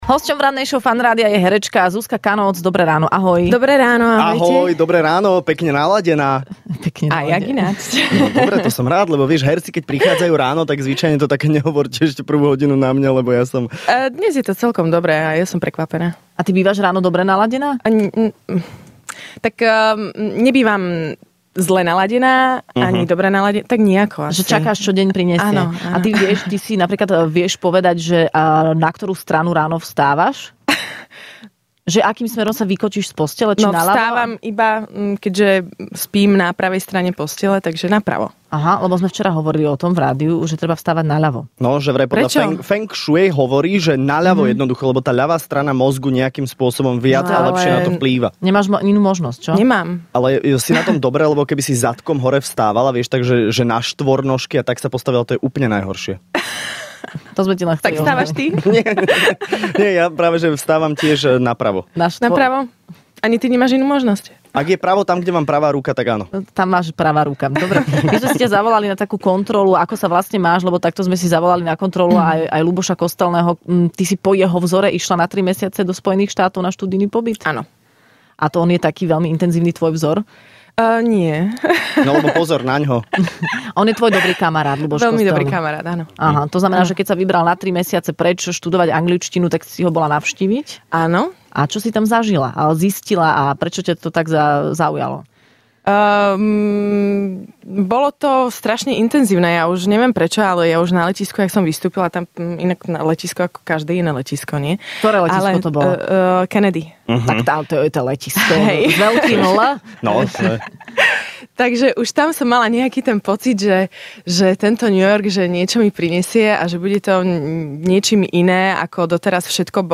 Hosťom v Rannej šou bola herečka Zuzka Kanócz, ktorú poznáte zo seriálu Ordinácia v ružovej záhrade ale aj z rôznych divadelných predstavení.